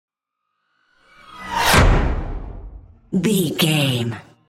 Sci fi whoosh to hit
Sound Effects
Atonal
dark
futuristic
intense
woosh to hit